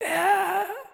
SFX_Mavka_Defeated_03.wav